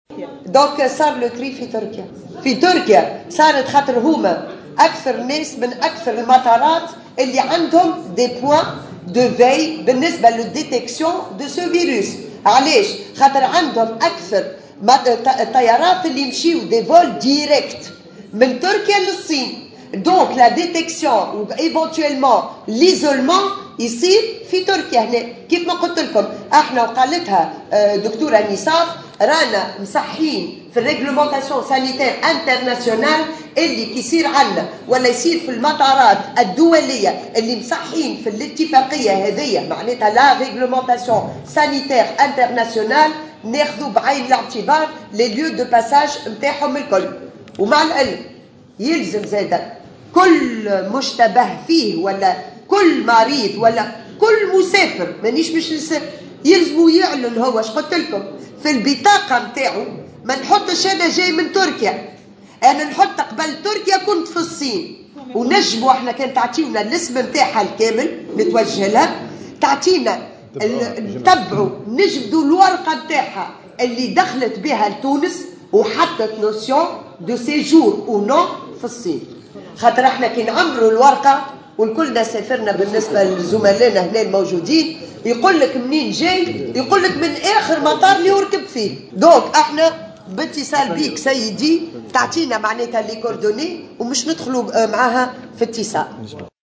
وزيرة الصحّة بالنيابة سنية بالشيخ